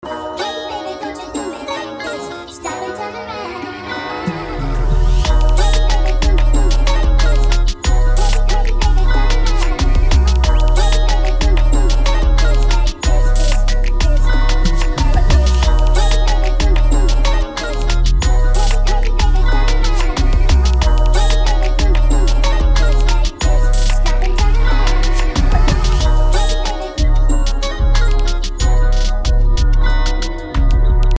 BPM: 185
Key: F# Major
Beat preview